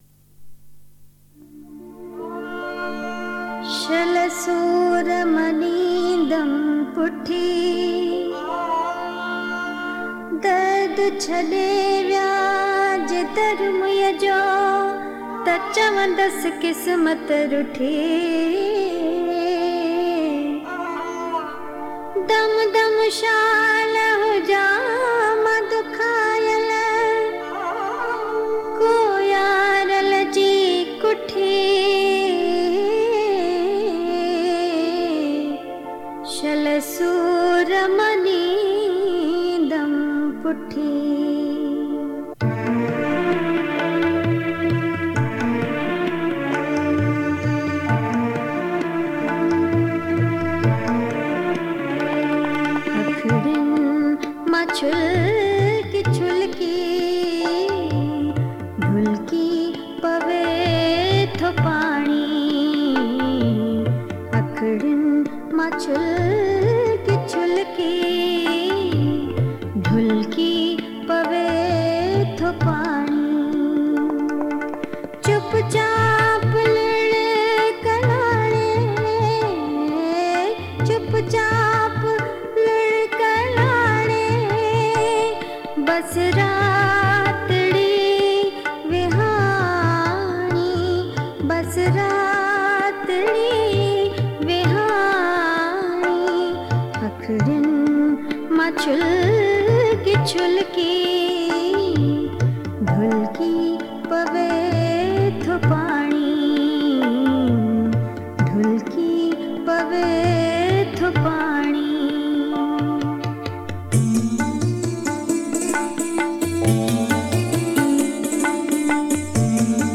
Sindhi Geet and Kalam